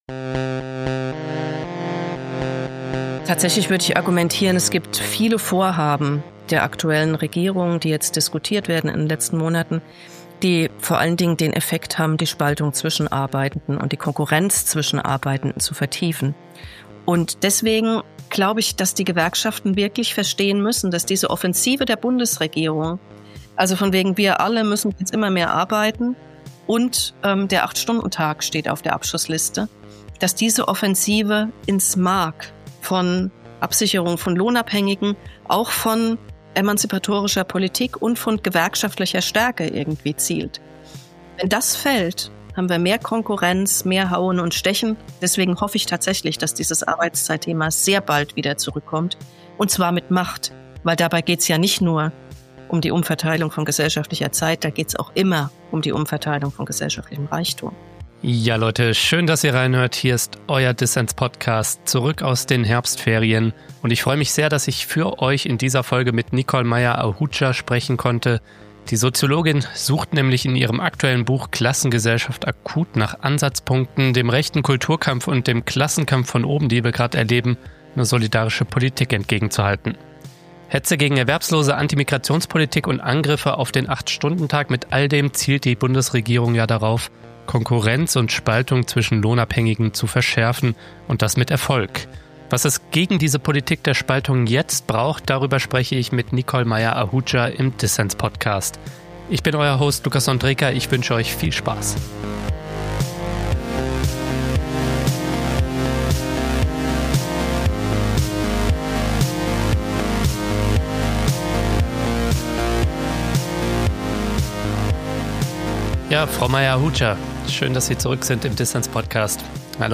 Ein Gespräch über die Rückkehr der Klassenfrage, handzahme Gewerkschaften und der Kampf für weniger Arbeit als verbindende Utopie.